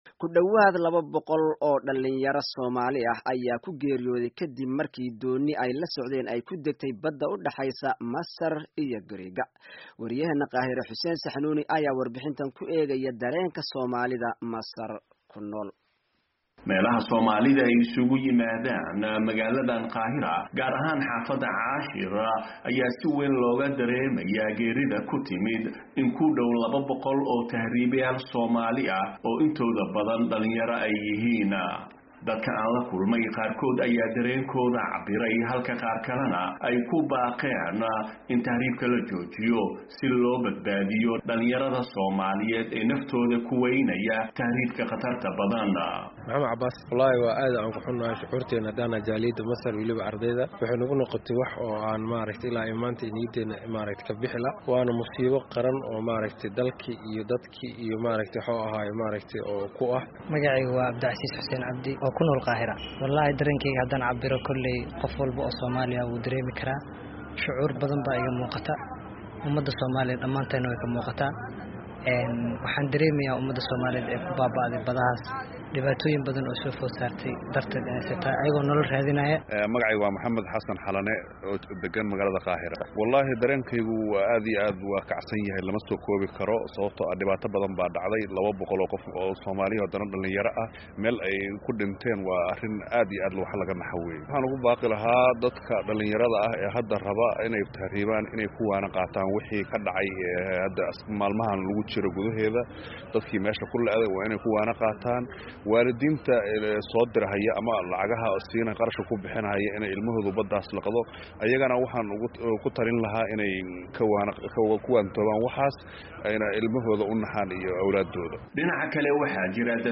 Warbixin Tahriibka